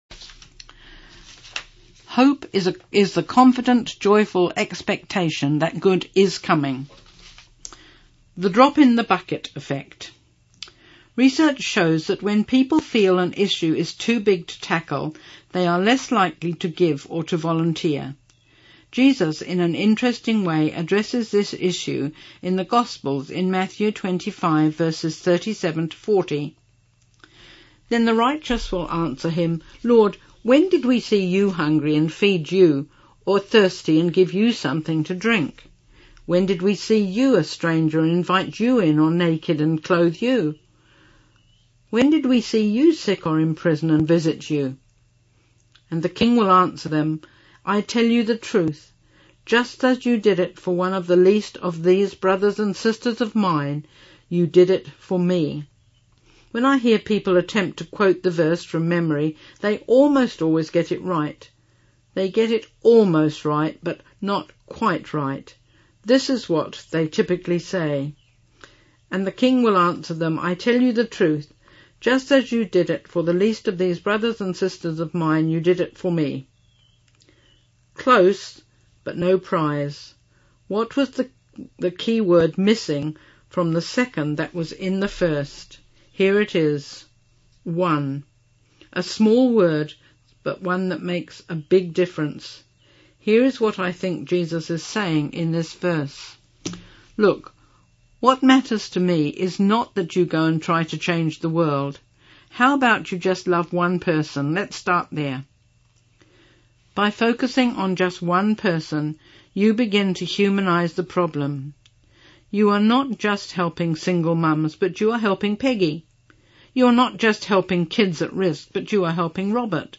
Genre: Speech..Released: 2016.